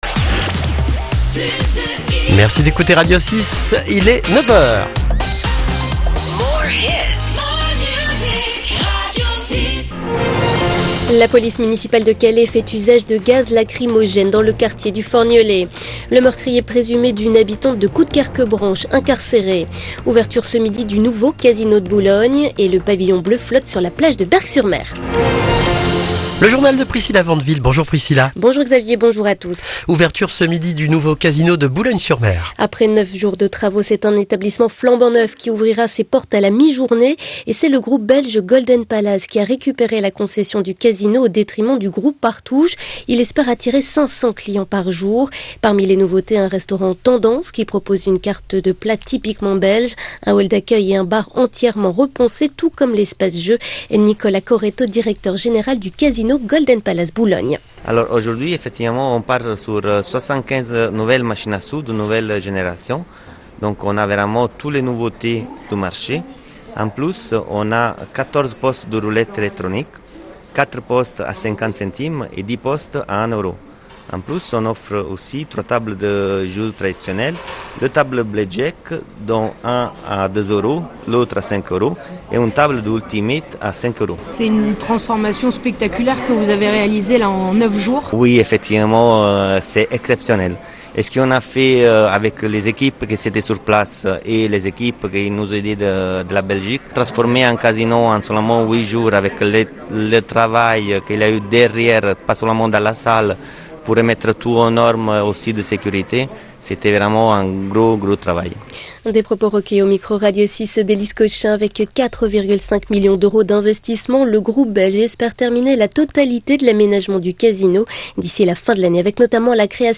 Le journal de 9h du mercredi 10 juillet